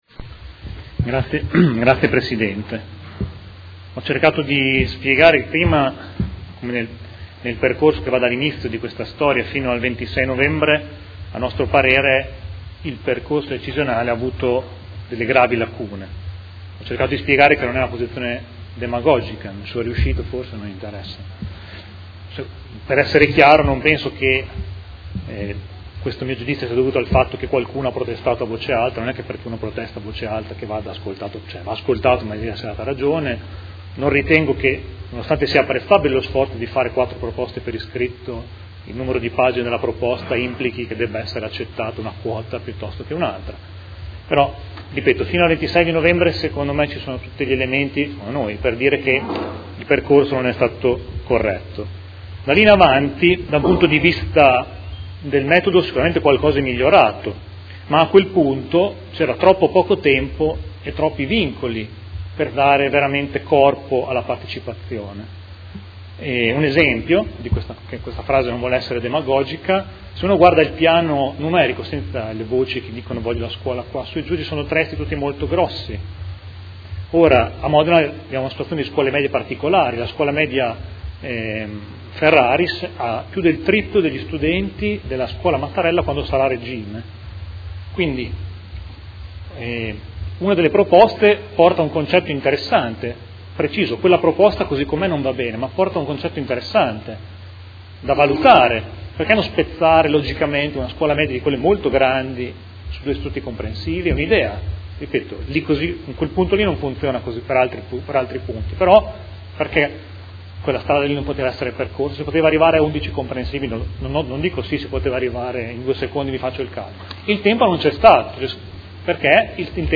Seduta del 14/01/2016. Dichiarazione di voto su delibera, emendamento e ordine del giorno riguardanti gli Istituti Comprensivi